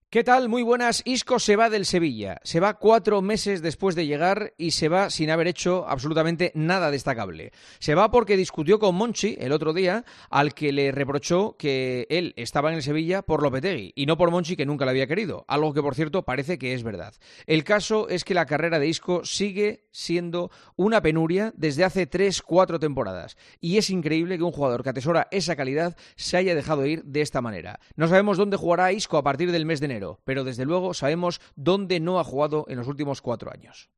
El director de 'El Partidazo de COPE' analiza la actualidad deportiva en 'Herrera en COPE'